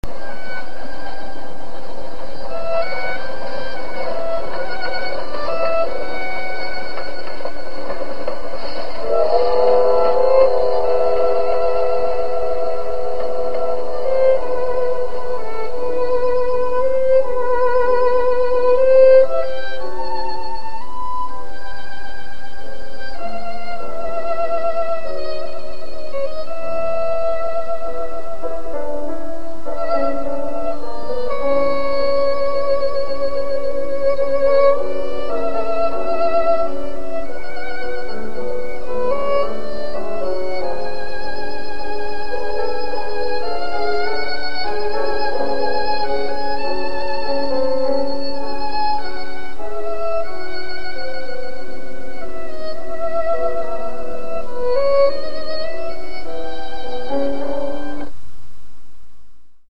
Подсобите определить композицию, пожалуйста (скрипка и фоно)
violin
piano) - Вокализ